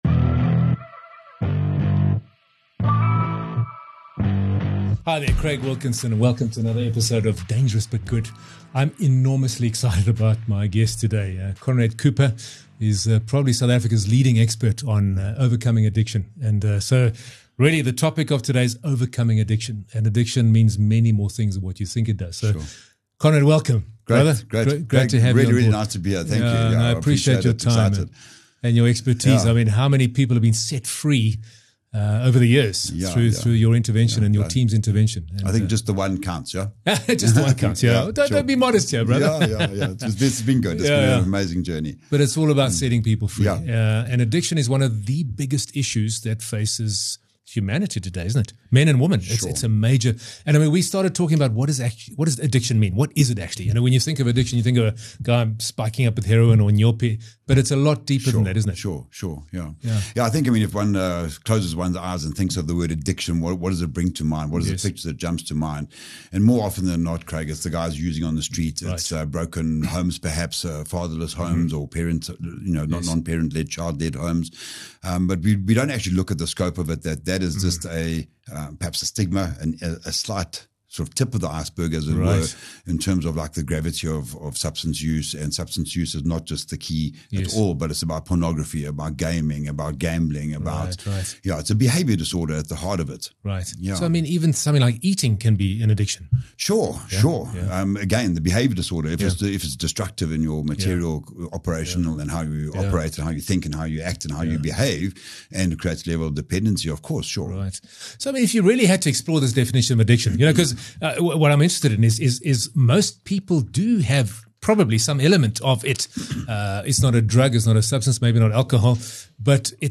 Addiction isn’t just about drugs and alcohol—it’s anything that controls you. In this powerful conversation